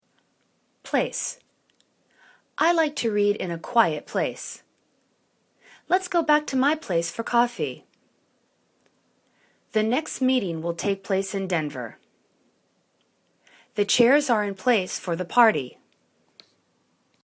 place  /pla:s/ n